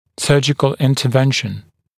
[‘sɜːʤɪkl ˌɪntə’venʃn][‘сё:джикл ˌинтэ’веншн]хирургическое вмешательство